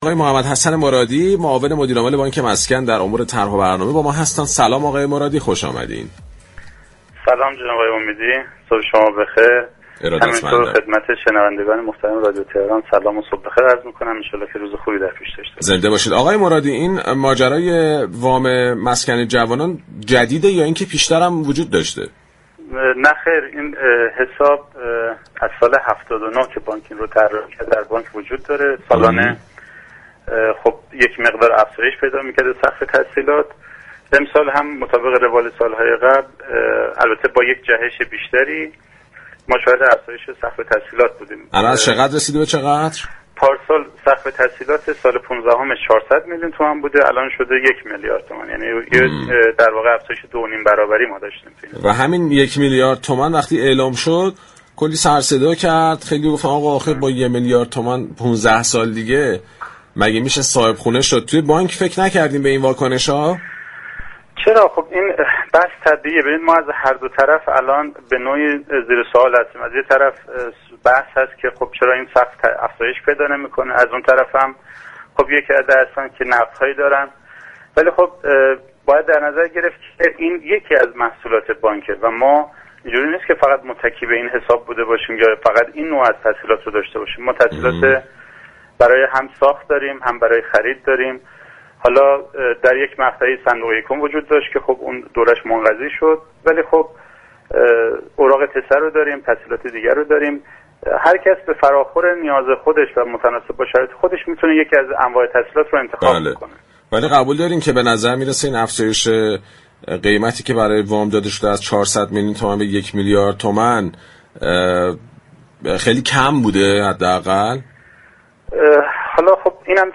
در گفتگو با برنامه پارك شهر